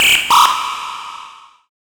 OLDRAVE 2 -L.wav